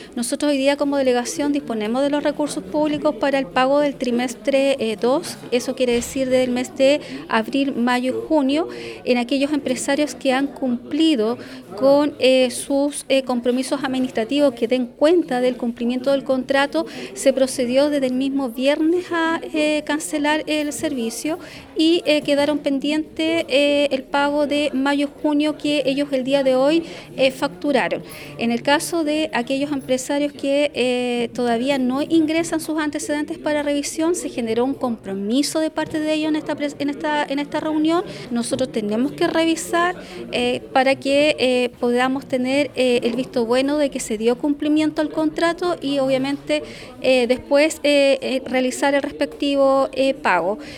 La paralización se originó por la no remuneración por la entrega de agua potable, según señaló la Delegada Presidencial Claudia Pailalef, actualmente se disponen de los recursos para pagar el servicio entregado por los empresarios quienes han entregado la documentación necesaria, haciendo énfasis en que no todos habían concluido este proceso.